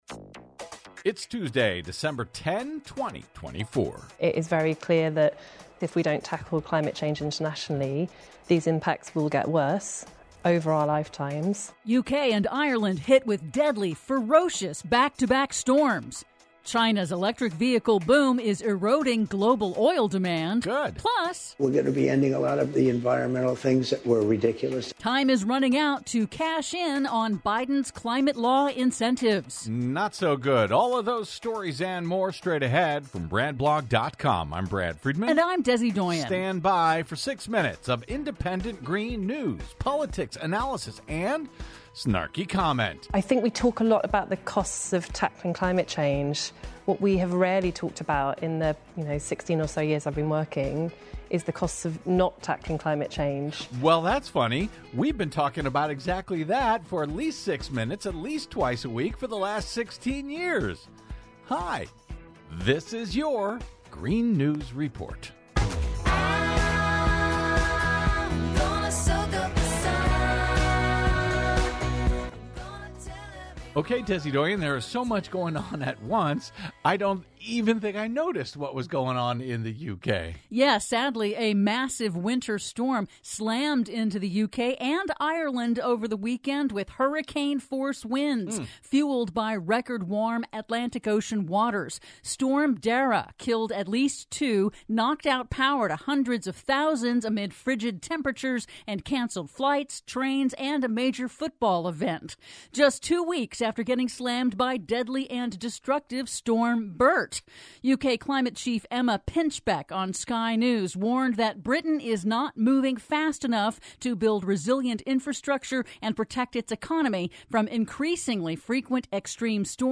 IN TODAY'S RADIO REPORT: U.K. and Ireland hit with deadly back-to-back storms; China's electric vehicle boom is eroding global demand for oil; PLUS: Time is running out to cash in on Biden's climate law incentives before Trump kills them... All that and more in today's Green News Report!